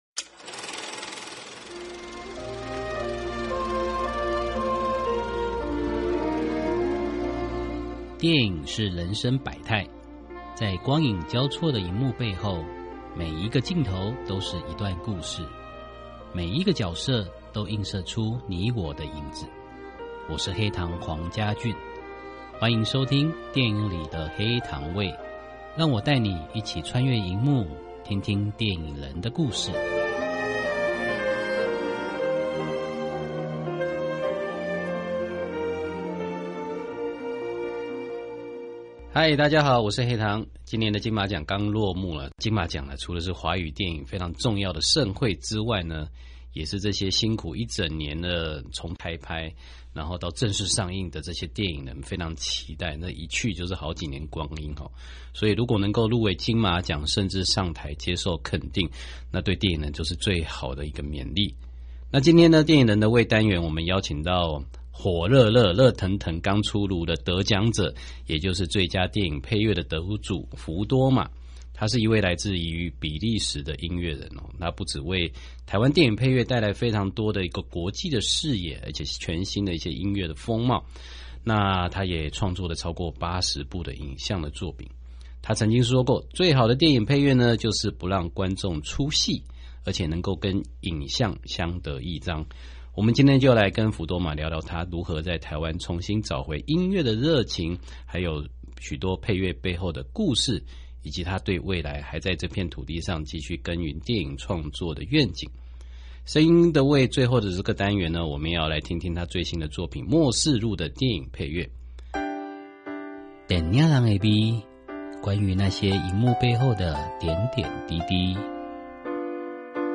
訪問大綱 1.